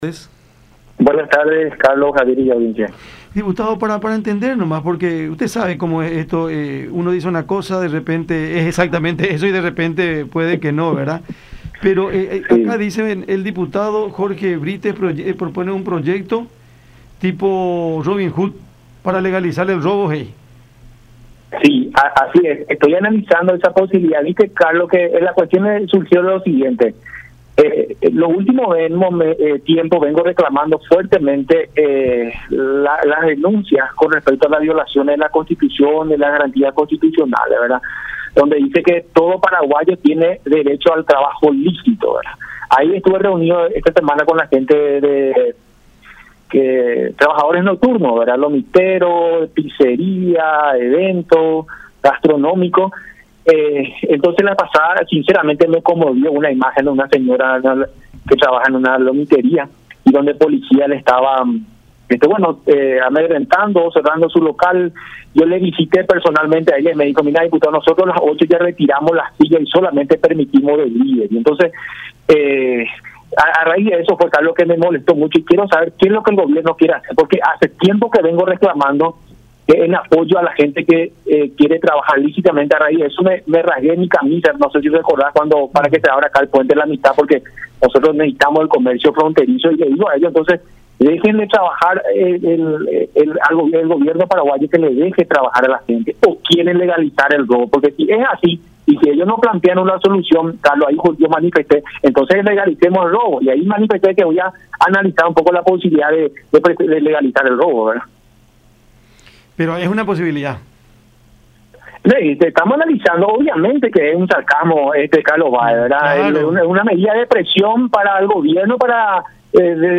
Jorge Brítez, diputado independiente.
“Es un sarcasmo. Es una medida de presión para el Gobierno con el objetivo de plantearle: ¿Vos querés que la gente robe? Quiero saber qué es lo que el Gobierno quiere hacer, porque no le deja trabajar a la gente, ¿o es que quiere legalizar el robo? Obviamente no voy a presentar ese proyecto”, expuso Brítez en charla con el programa Cada Siesta por La Unión.